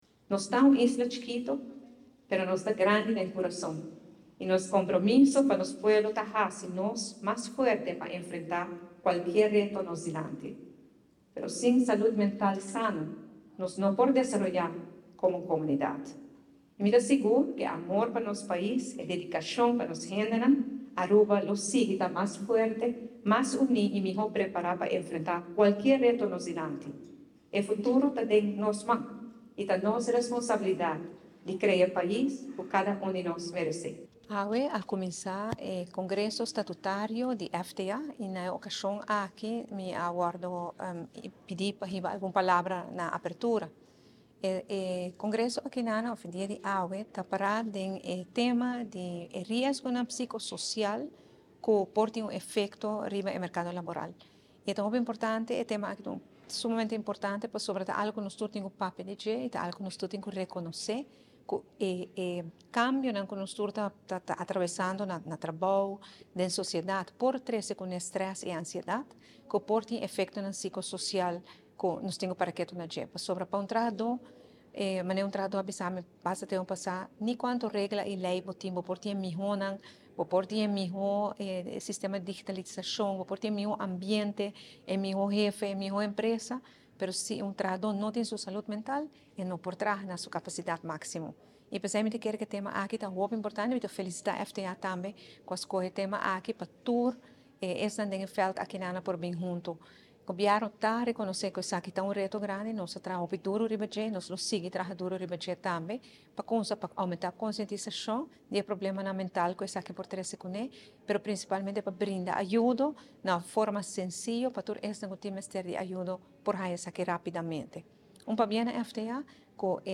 ORANJESTAD – Recientemente Prome Minister Evelyn Wever-Croes tabata presente na e Congreso Statutario di FTA, na unda el a wordo invita pa duna un discurso.